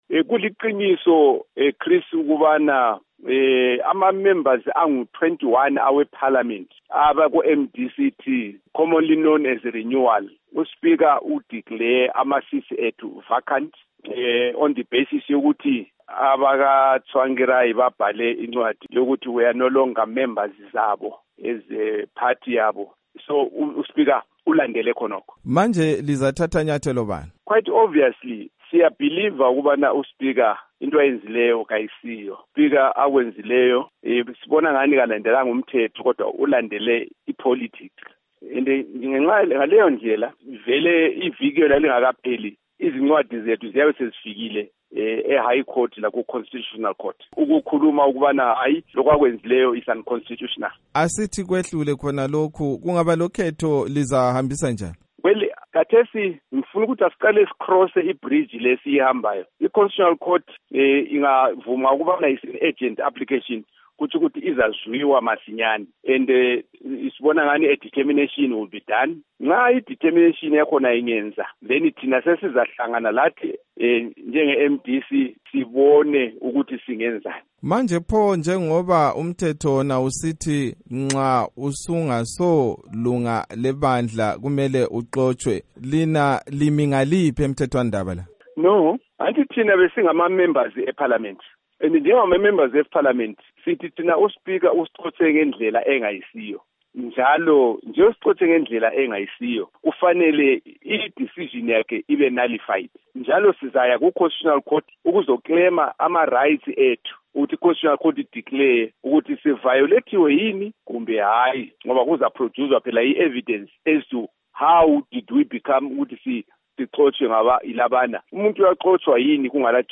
Ingxoxo loMnu. Sam Sipepa Nkomo